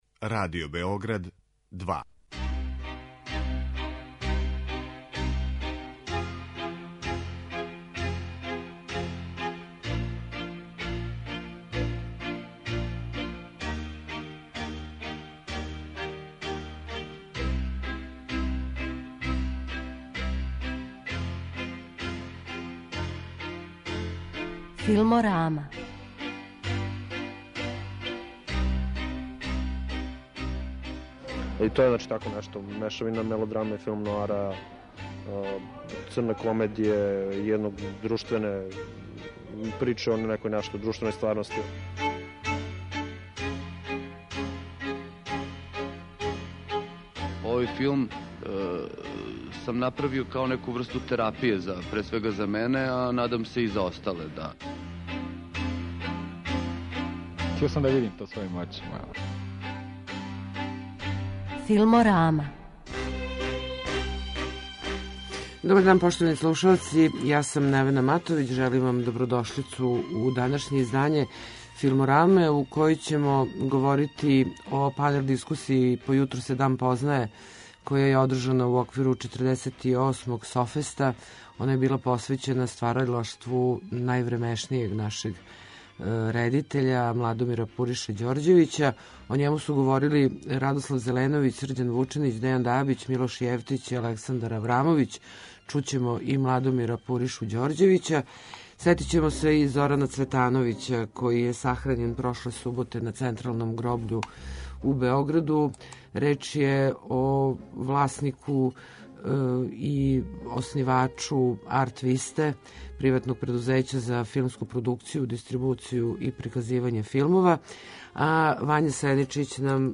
Чућете и разговоре са управо завршеног Башта Феста, међународног фестивала кратког играног филма, који пре свега промовише младе ауторе.